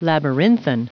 Prononciation du mot labyrinthine en anglais (fichier audio)
Prononciation du mot : labyrinthine